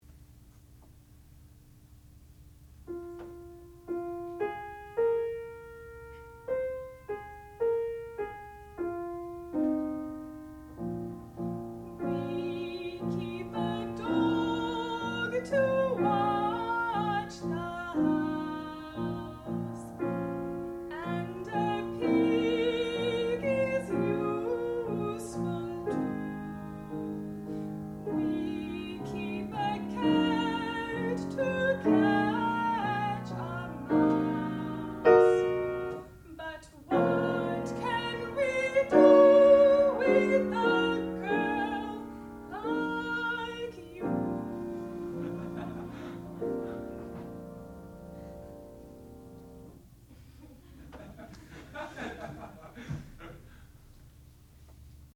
sound recording-musical
classical music
piano
soprano
Student Recital